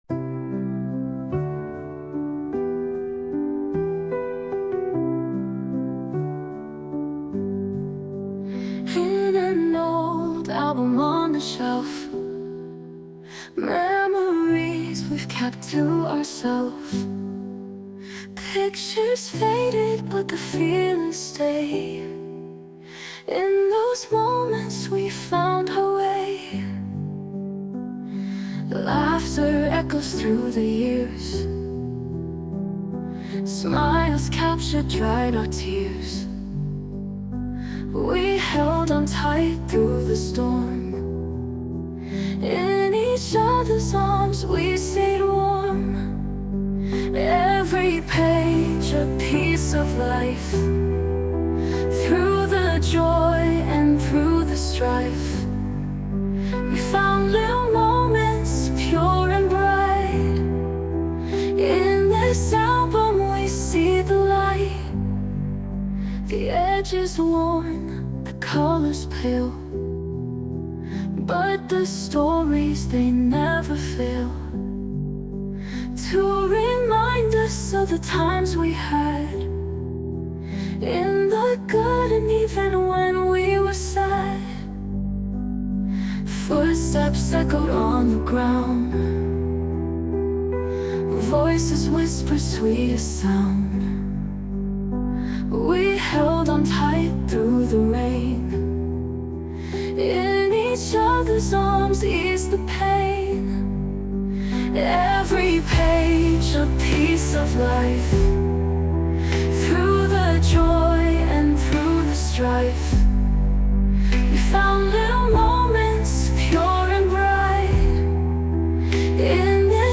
洋楽女性ボーカル著作権フリーBGM ボーカル
著作権フリーBGMです。
女性ボーカル（洋楽）曲です。